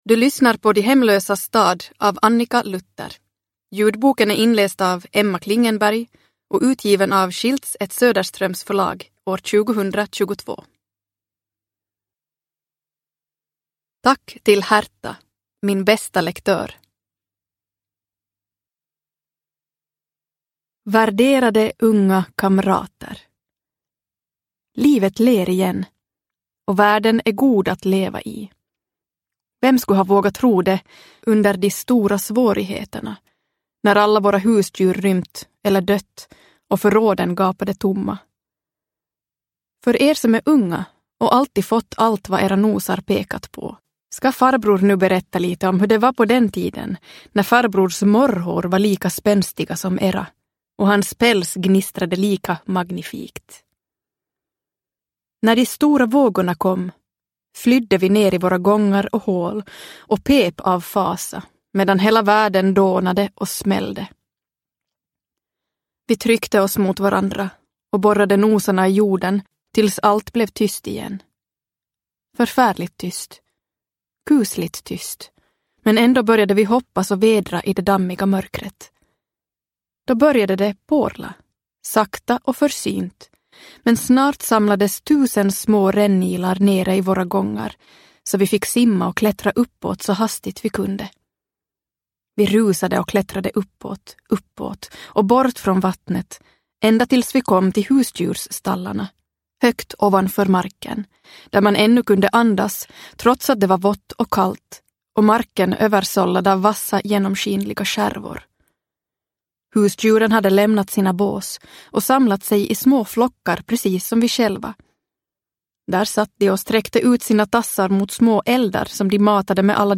De hemlösas stad – Ljudbok – Laddas ner